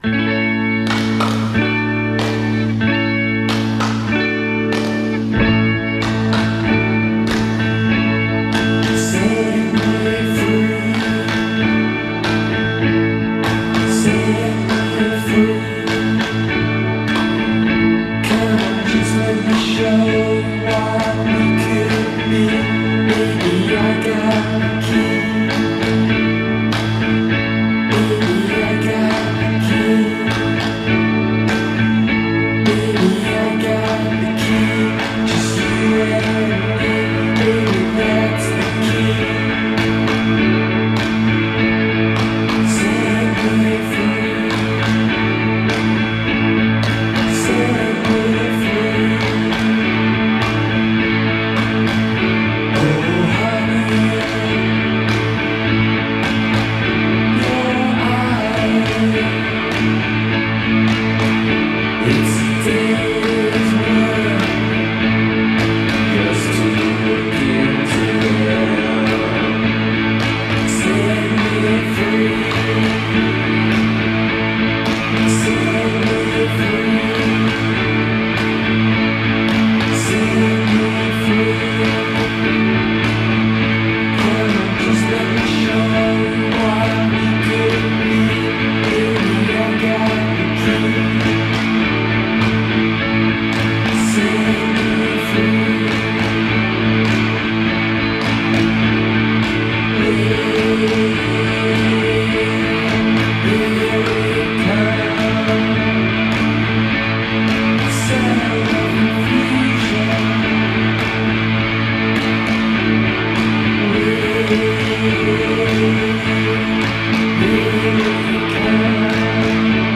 Shoegaze and other love songs.
A dose of Shoegaze tonight